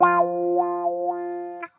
CC GUITAR LOOPS WAH 100 Fm " GTCC WH 06 - 声音 - 淘声网 - 免费音效素材资源|视频游戏配乐下载